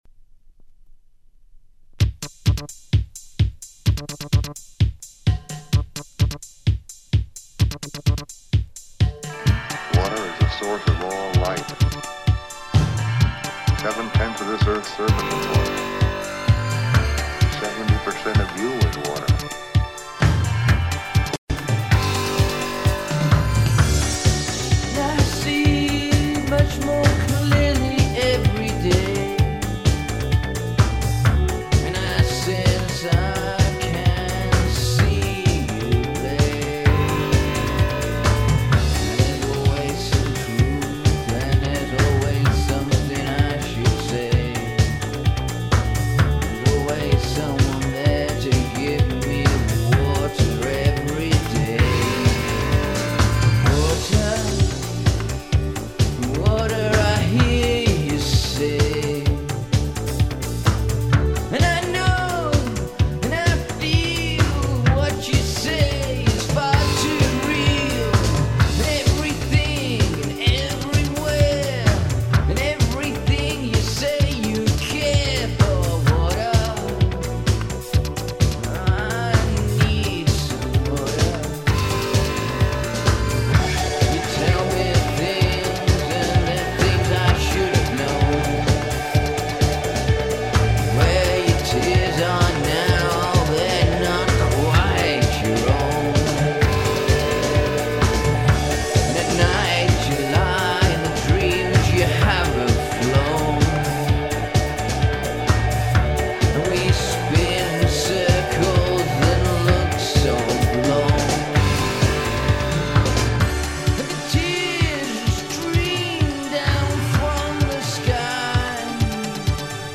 keyboard overdubs